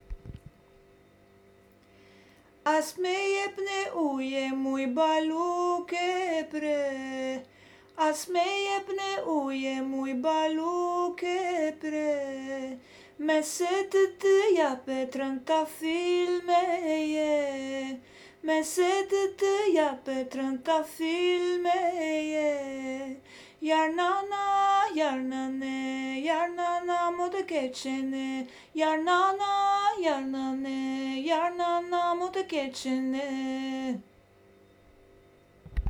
Albanian folk song